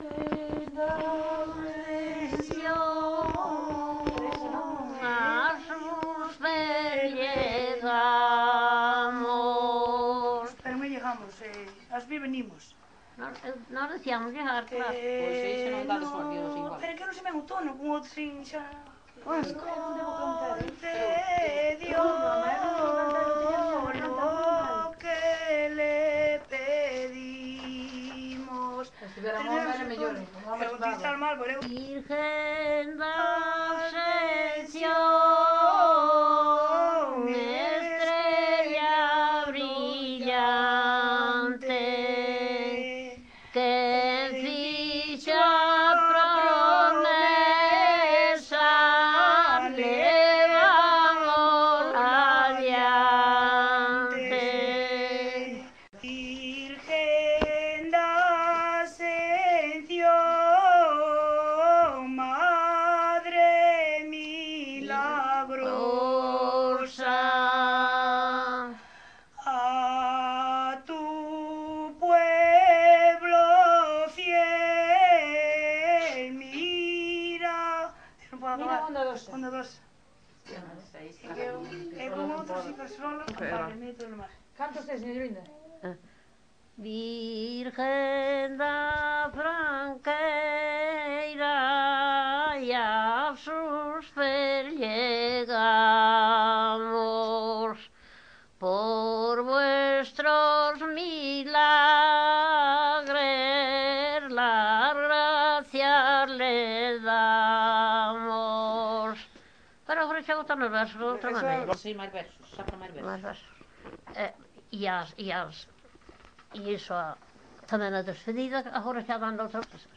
Coplas relixiosas
Tipo de rexistro: Musical
Lugar de compilación: Neves, As - Taboexa (Santa María) - Carrasqueira, A
Soporte orixinal: Casete
Instrumentación: Voz
Instrumentos: Voces femininas